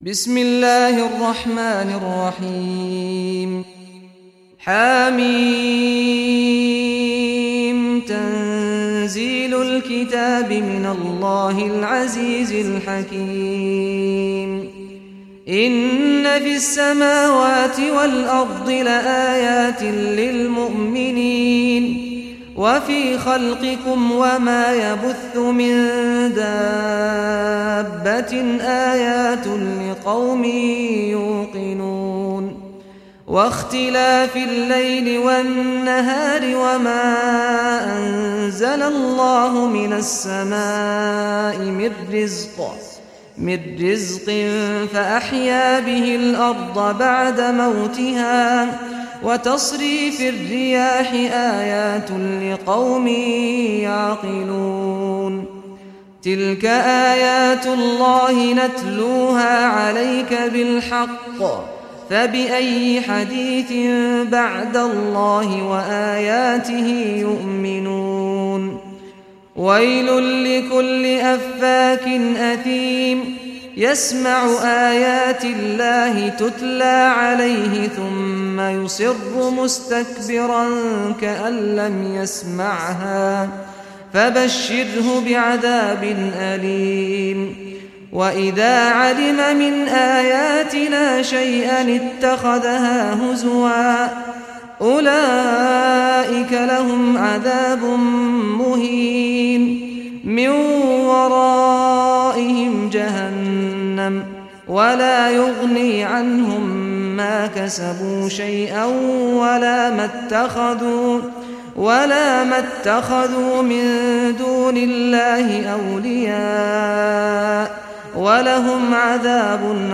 Surah Al Jathiya Recitation by Sheikh Saad Ghamdi
Surah Al Jathiya, listen or play online mp3 tilawat / recitation in Arabic in the beautiful voice of Sheikh Saad al Ghamdi.